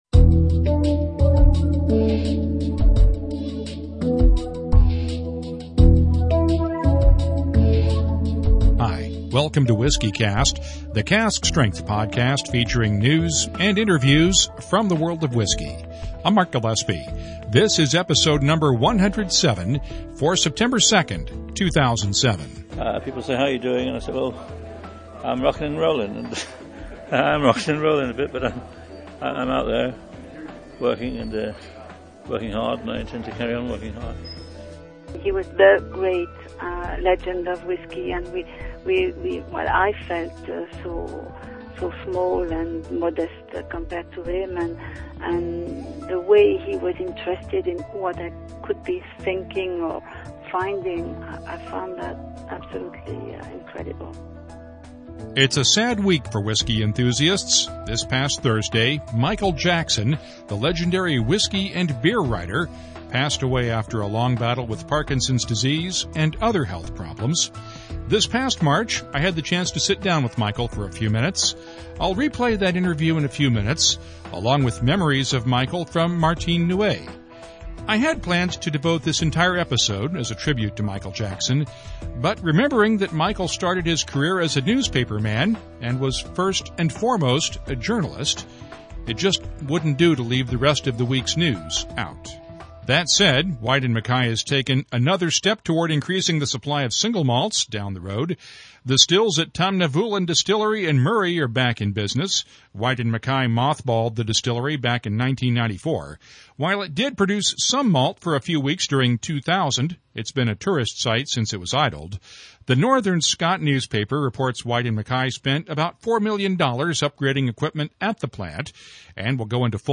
In March, I had the chance to talk with Michael, and you’ll hear part of that interview on this episode